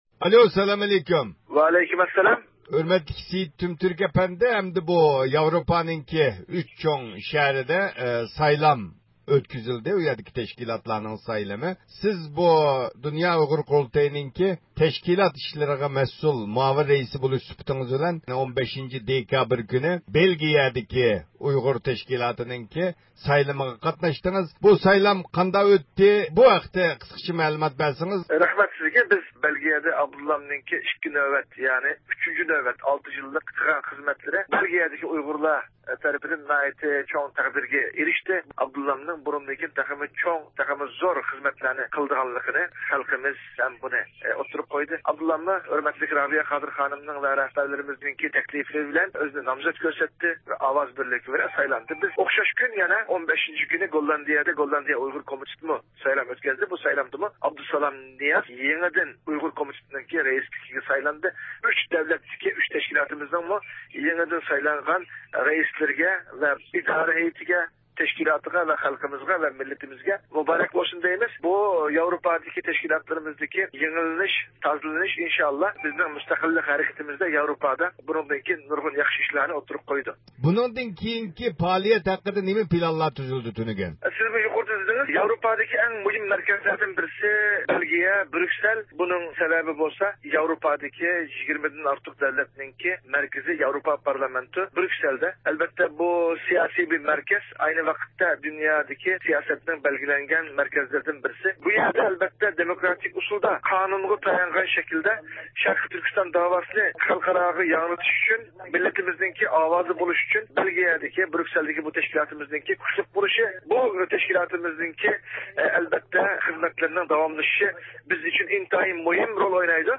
تېلېفون سۆھبىتى